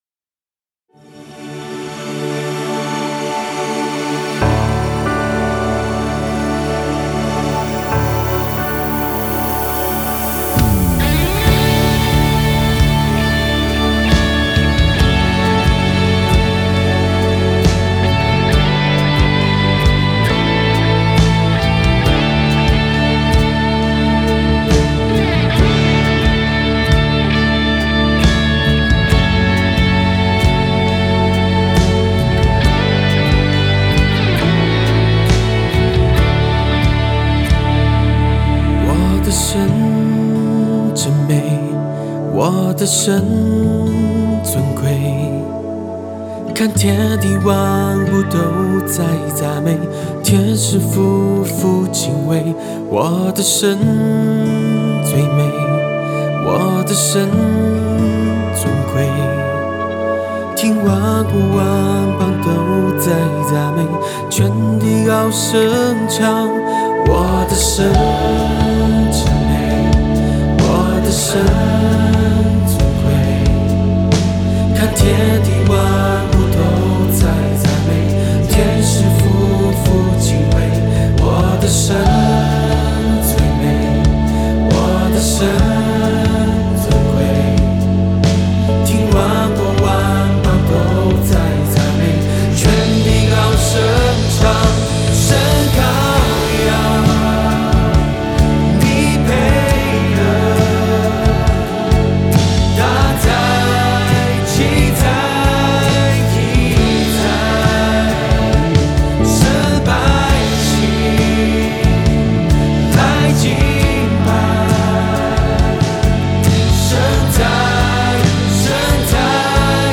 前奏 → 主歌(兩遍) → 副歌(兩遍) → 間奏 → 主歌 → 副歌(四遍) → 尾句(兩遍) → 尾奏